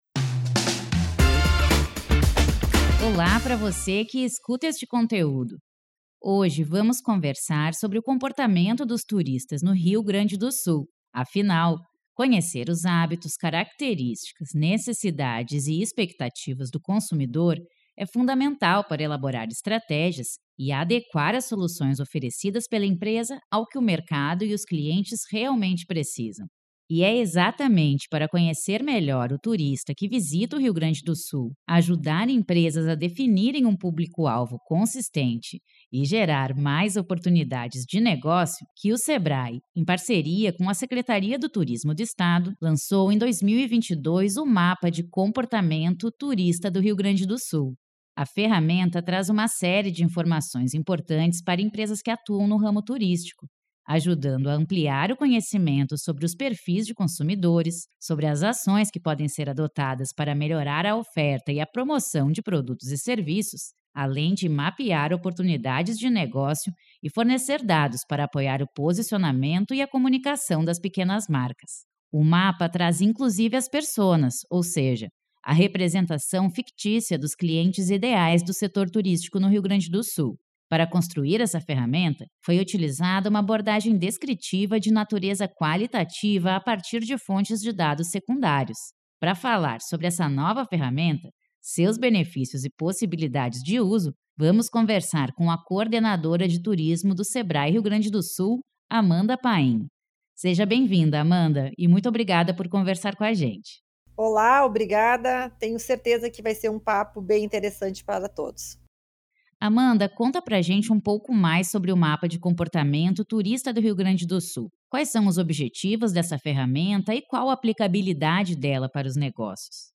Em um bate-papo que você pode ouvir no decorrer deste artigo